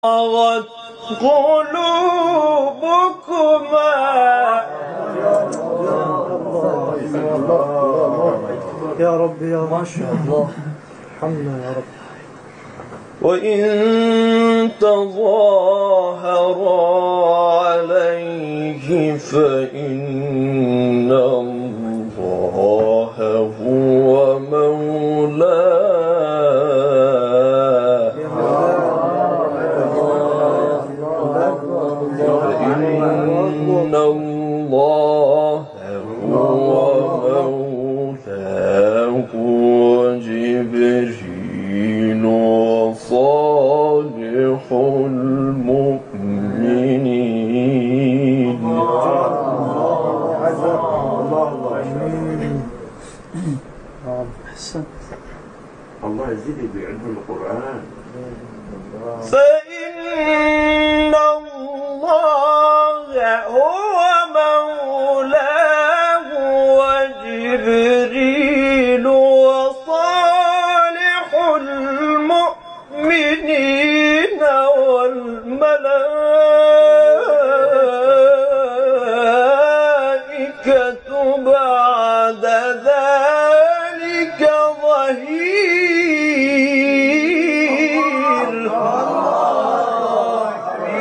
گروه شبکه اجتماعی: نغمات صوتی از تلاوت‌های قاریان به‌نام کشور را می‌شنوید.
سوره تحریم در مقام بیات